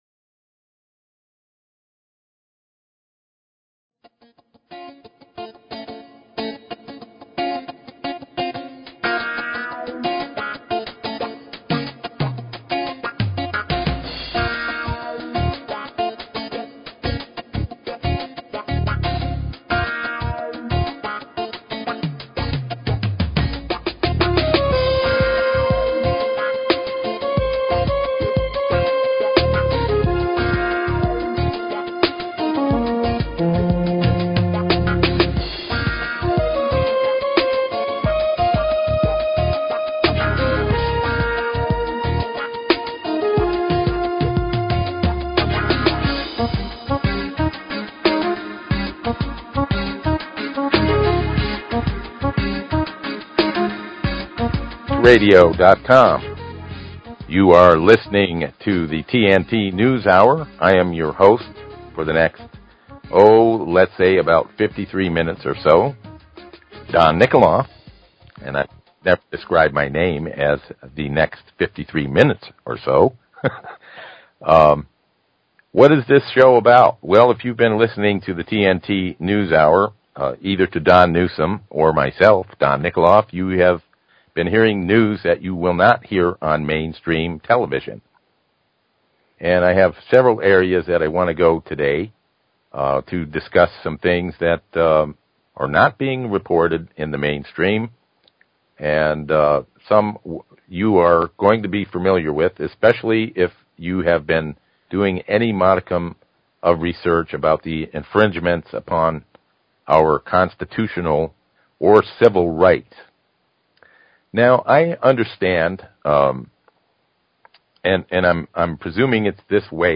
Talk Show Episode, Audio Podcast, The_TNT_Hour and Courtesy of BBS Radio on , show guests , about , categorized as